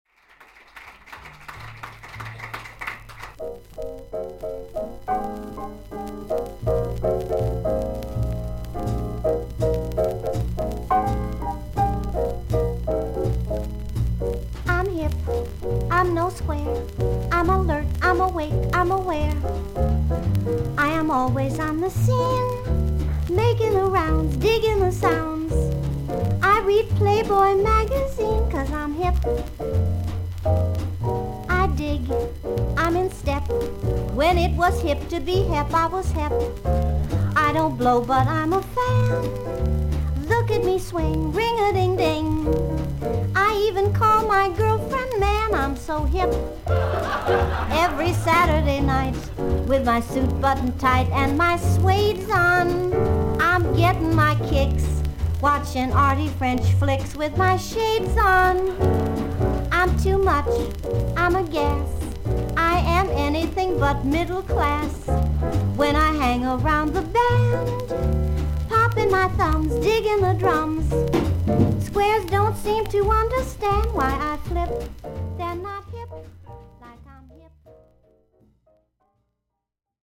少々軽いパチノイズの箇所あり。全体的に少々サーフィス・ノイズあり。音はクリアです。
独特の可憐な歌声のシンガー/ピアニスト。
ロンドン、ロニー・スコッツでのライヴ録音。